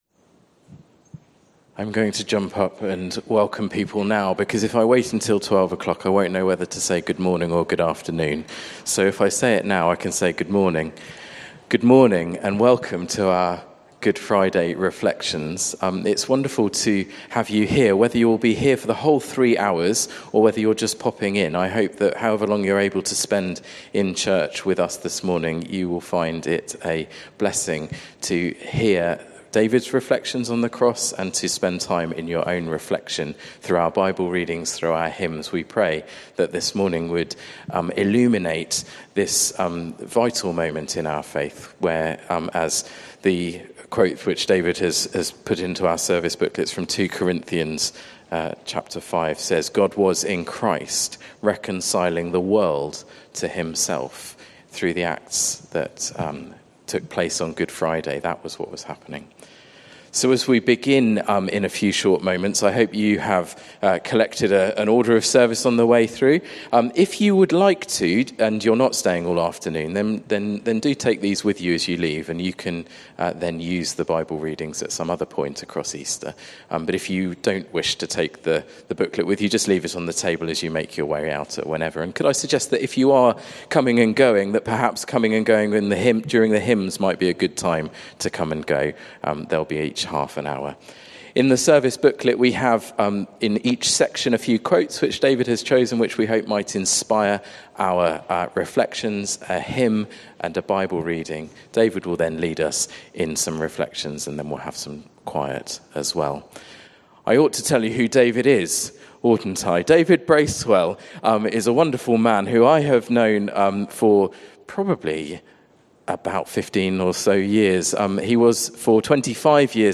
Service Type: Good Friday meditation